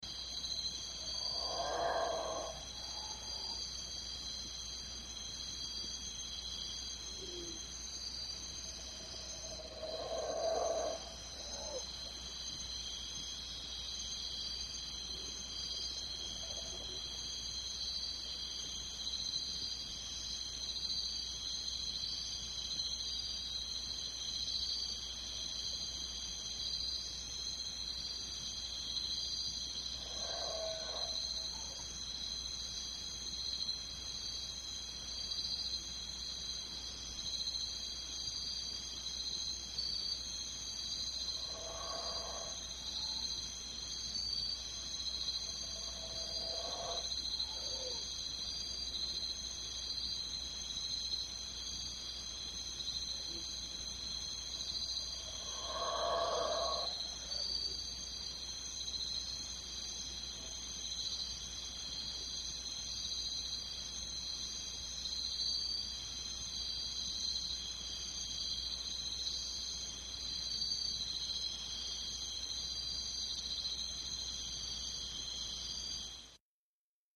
Guatemalan jungle at night with Howling Monkeys, El Mirador